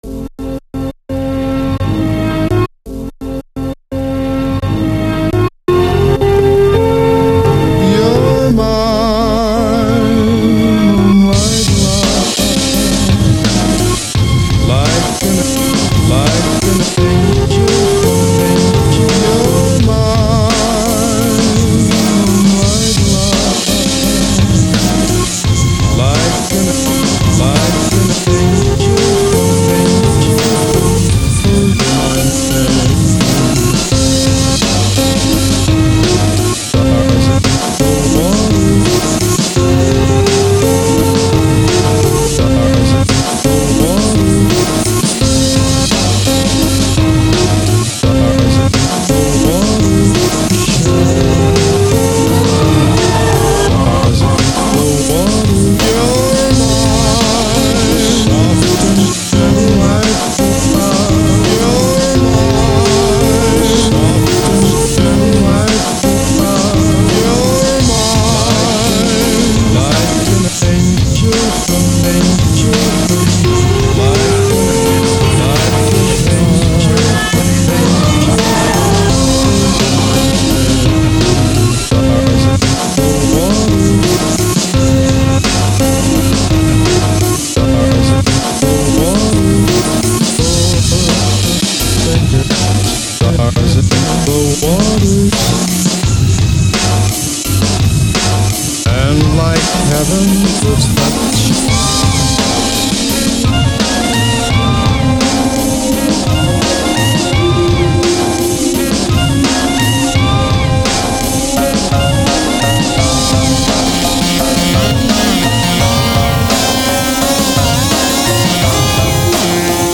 THats some strange funky music man.